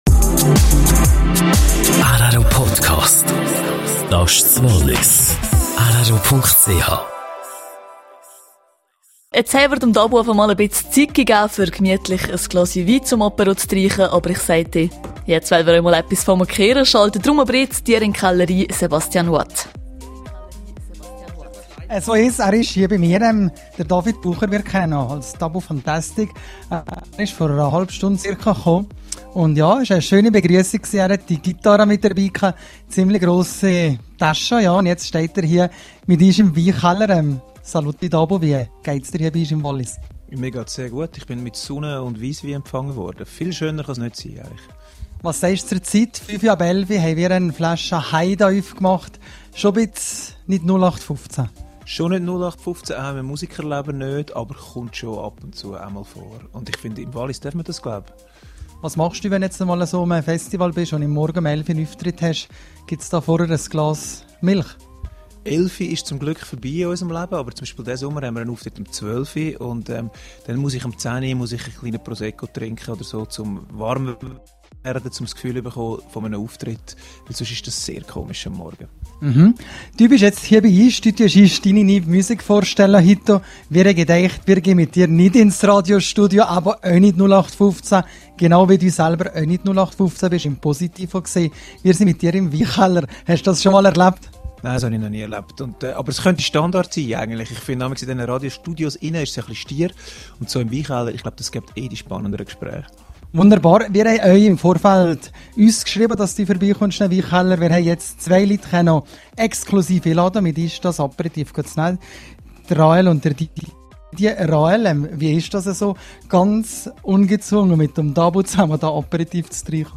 Dabu Fantastic bei rro: Interview live aus dem Weinkeller.